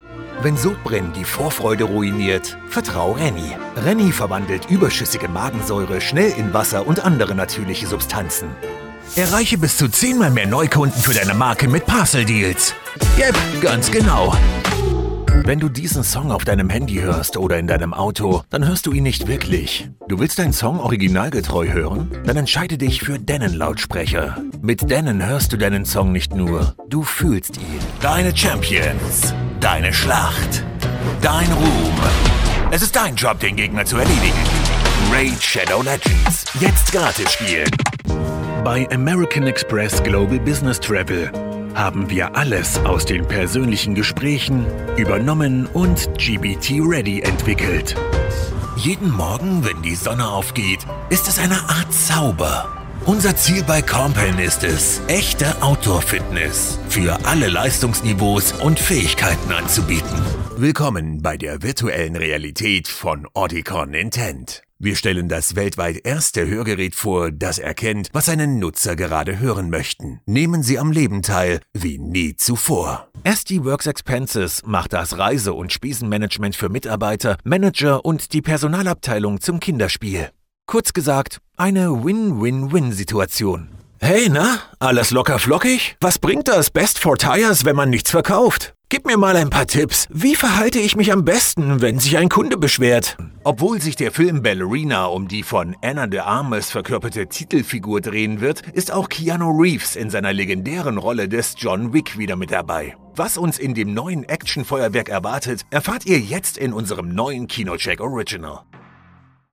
Fresco
Joven
Dinámica